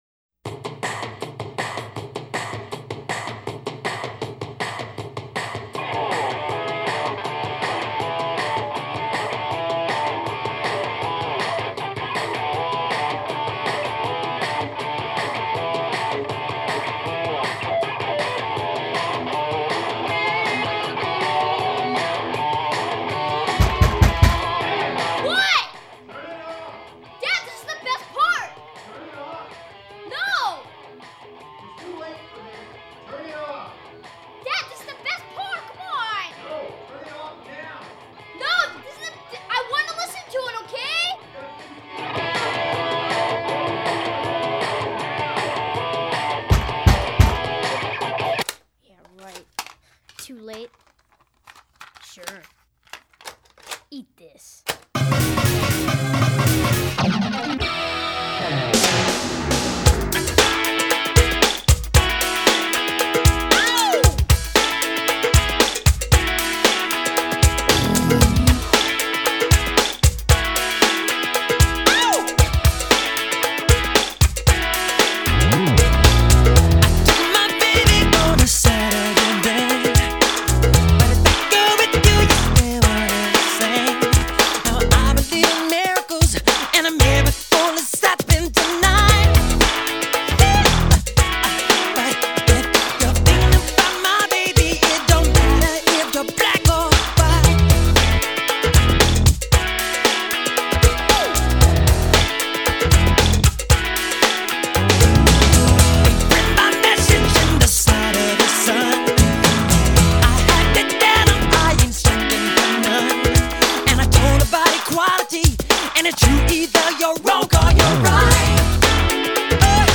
Жанр: Soul